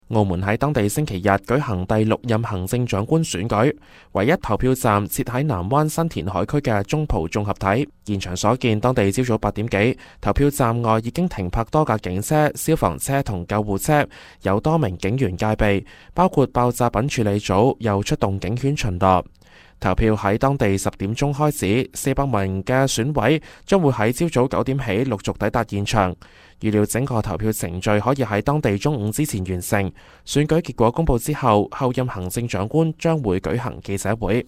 news_clip_20904.mp3